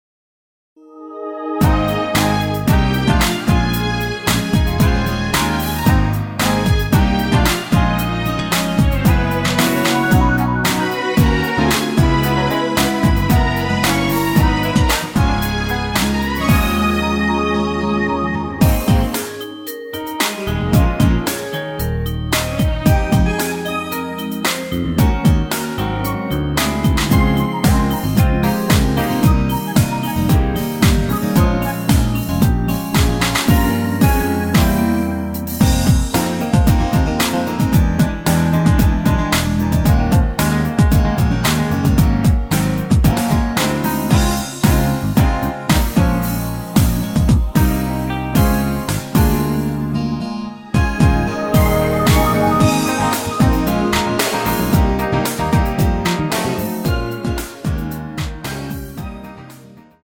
축가를 짧게 하셔야 될때 사용하시기 좋은 MR 입니다.
Ab
앞부분30초, 뒷부분30초씩 편집해서 올려 드리고 있습니다.
중간에 음이 끈어지고 다시 나오는 이유는